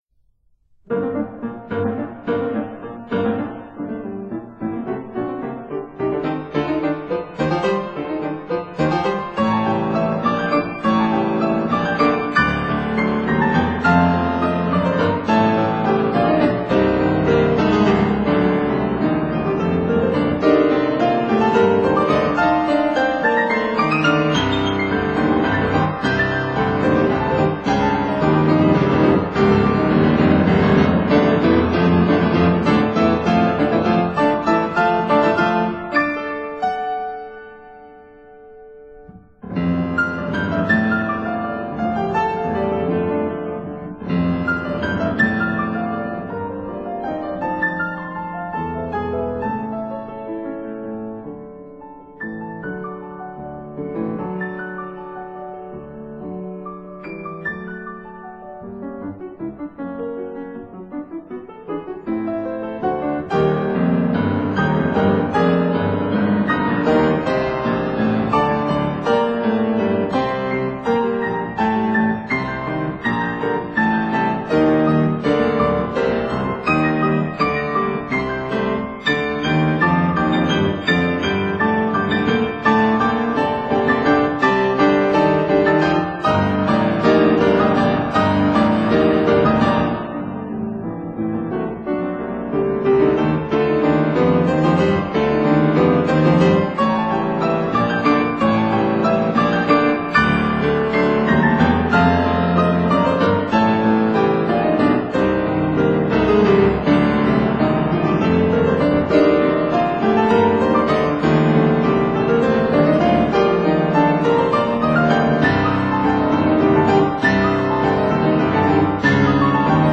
for 2 pianos
for piano four hands